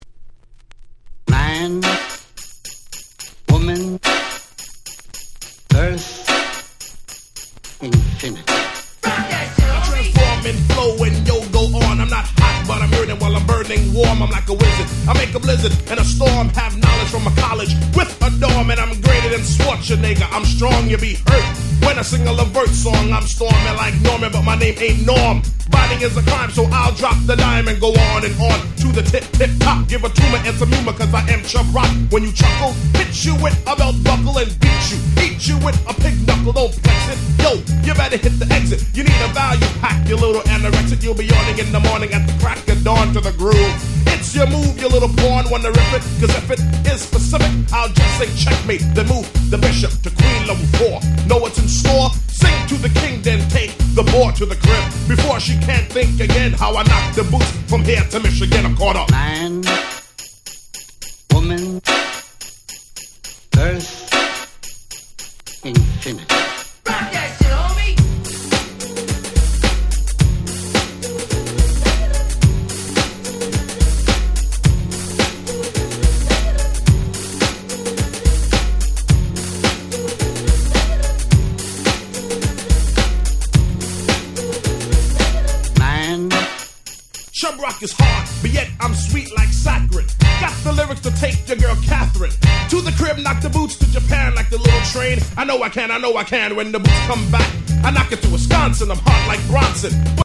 88' Smash Hit Hip Hop !!
チャブロック ブーンバップ Boom Bap ミドルスクール Old School オールドスクール